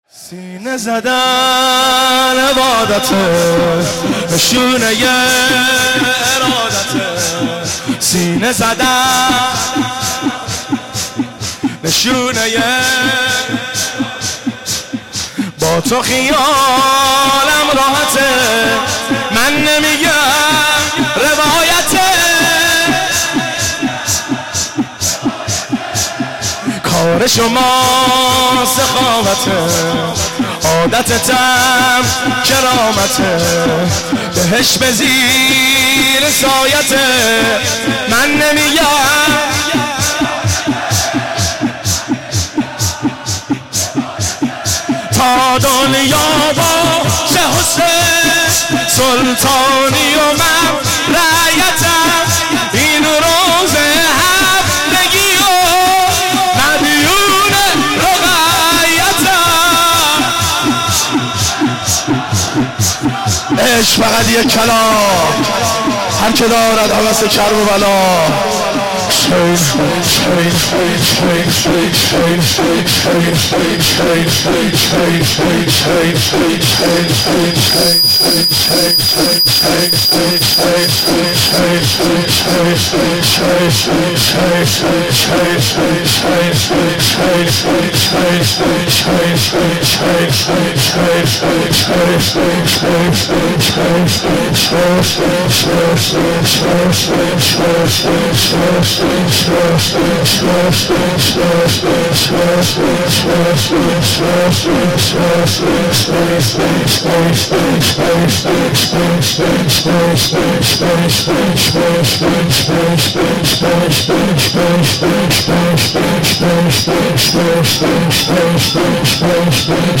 مناسبت : شب بیست و چهارم رمضان
قالب : شور